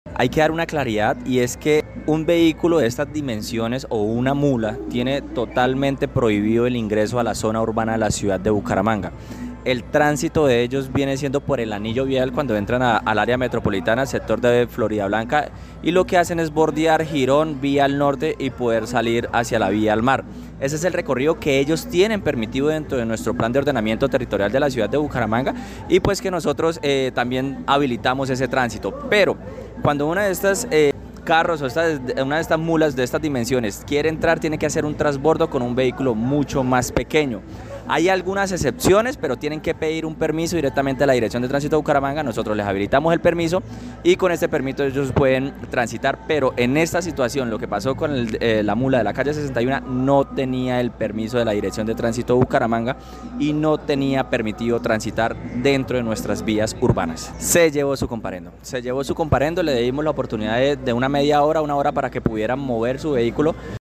Jhair Manrique, director de Tránsito de Bucaramanga
Escuche aquí al director de tránsito: